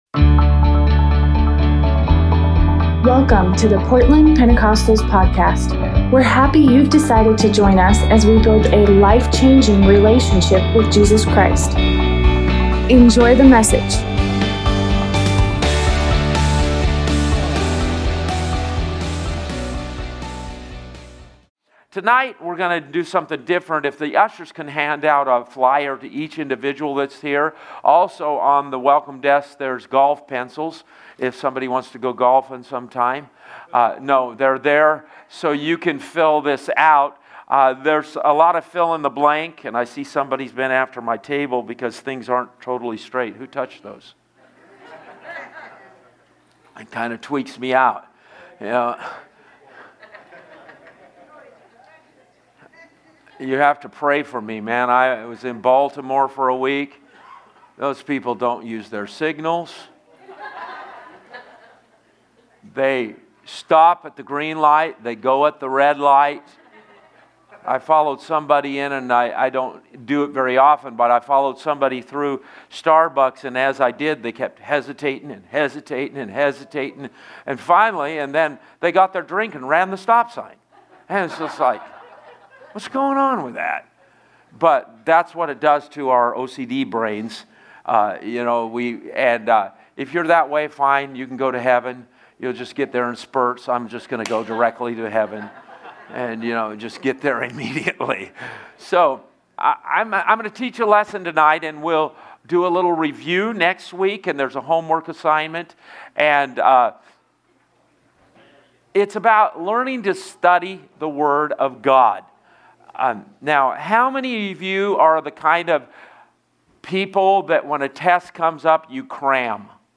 Tuesday night Bible study